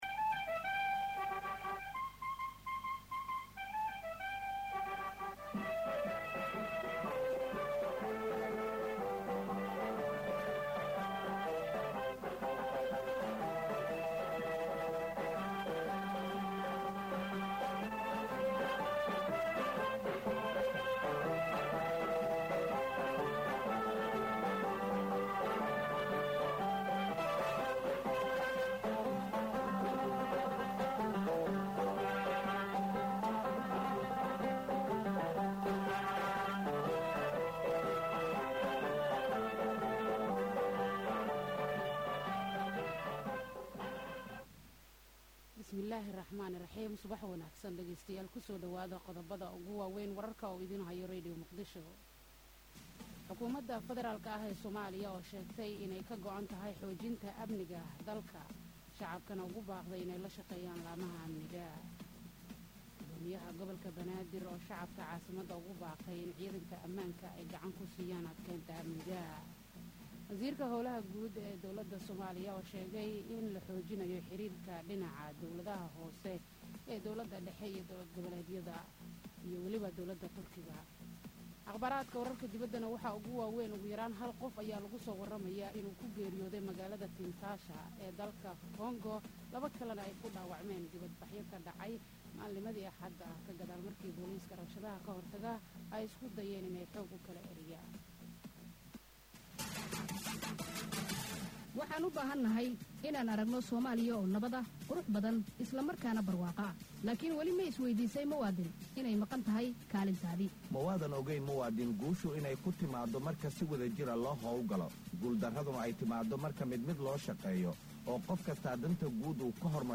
Dhageyso Warka Subax ee Radio Muqdisho.